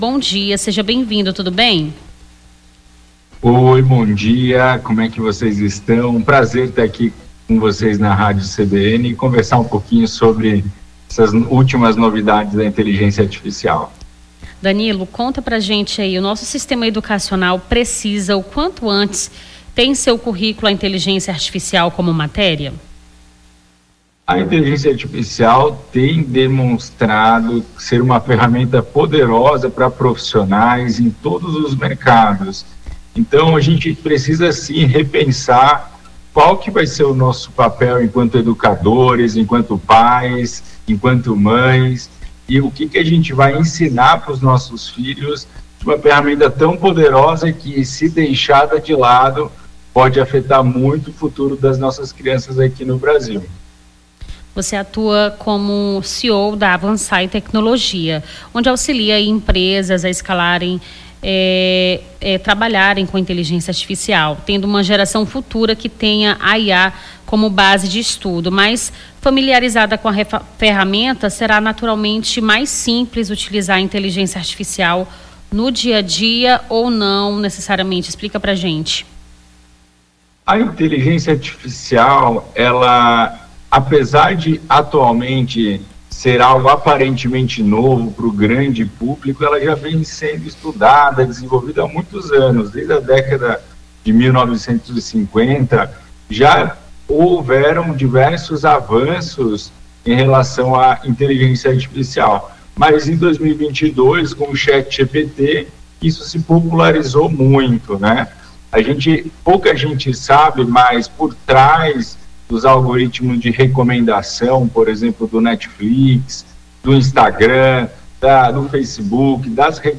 Nome do Artista - CENSURA - ENTREVISTA (EDUCAÇÃO E IAS) 03-04-25.mp3